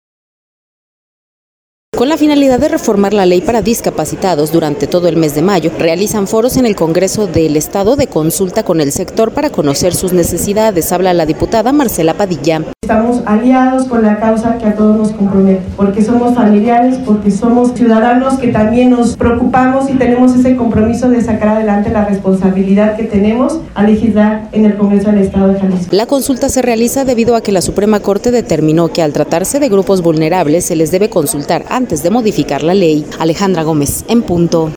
Con la finalidad de reformar la Ley para discapacitados, durante todo el mes de mayo, realizan foros en el Congreso del Estado, de consulta con el sector para conocer sus necesidades, habla la diputada, Marcela Padilla: